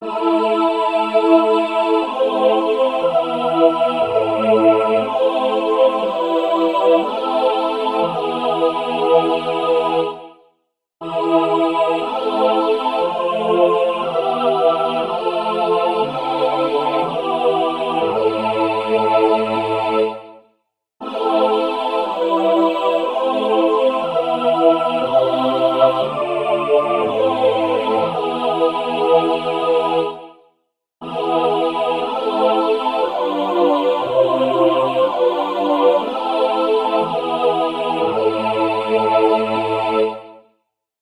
Key: a minor